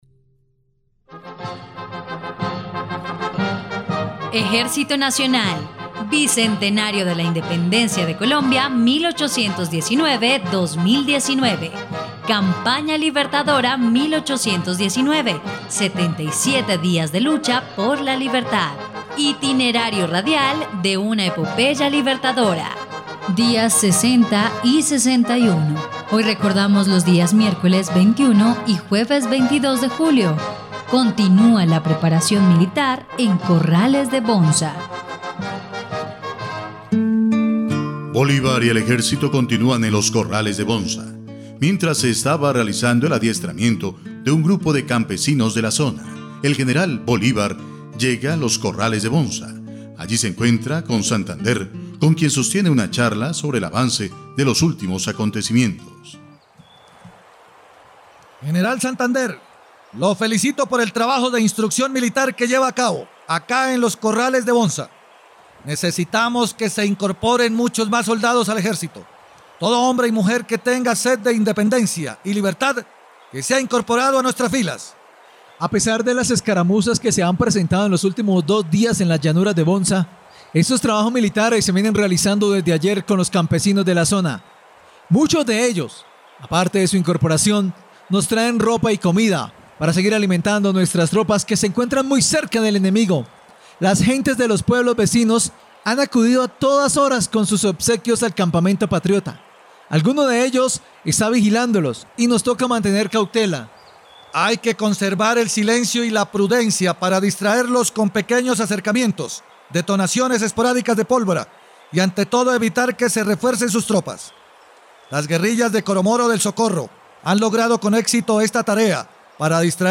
dia_60_y_61_radionovela_campana_libertadora.mp3